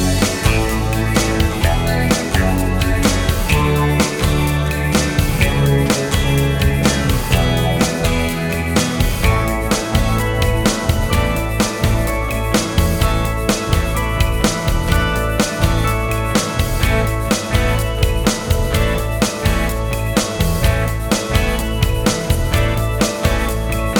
no Backing Vocals Rock 4:17 Buy £1.50